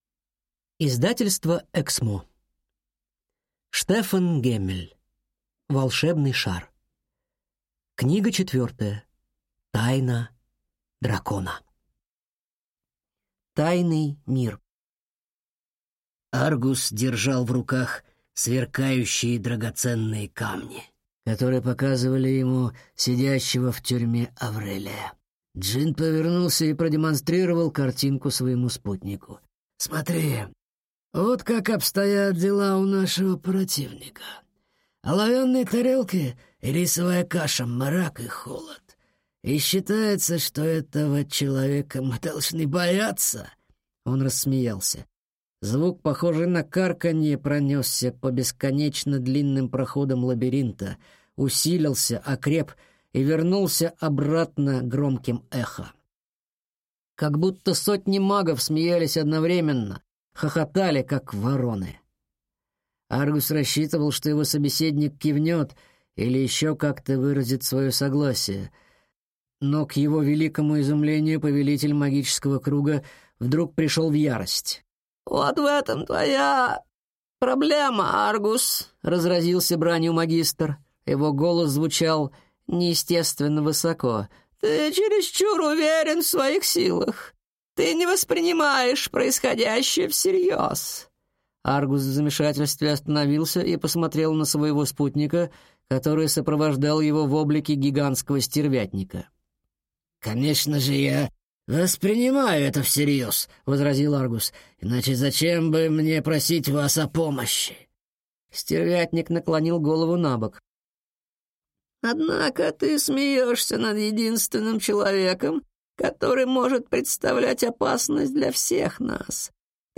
Аудиокнига Тайна дракона | Библиотека аудиокниг